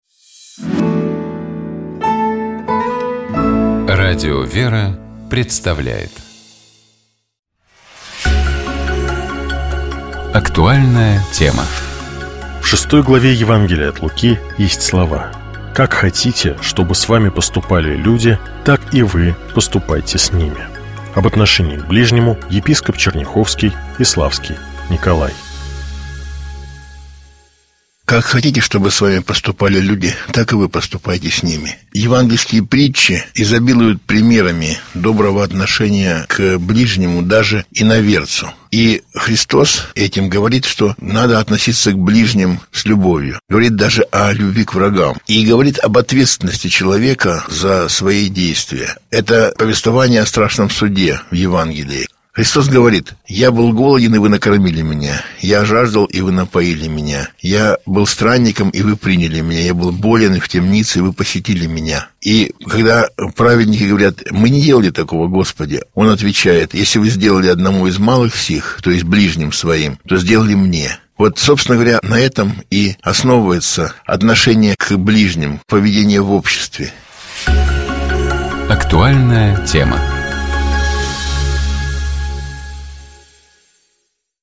Об отношении к ближнему, — епископ Черняховский и Славский Николай.